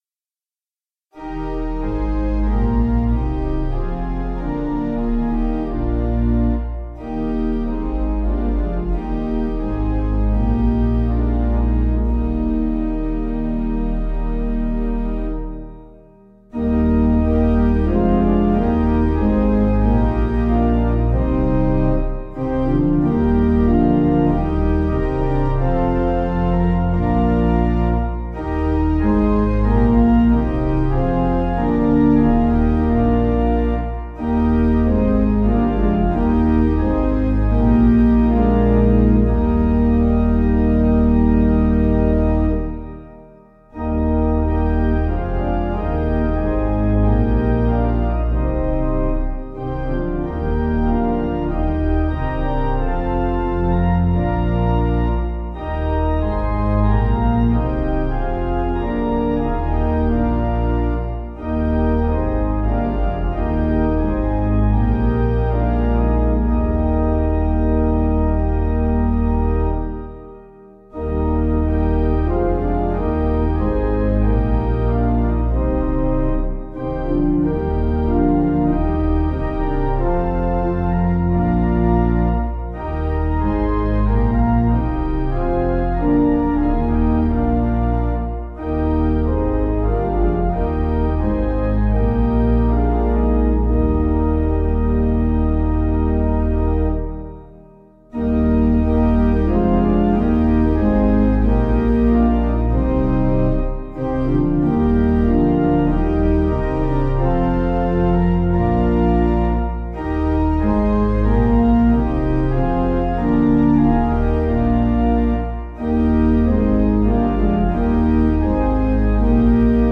Meter:    88.88
organpiano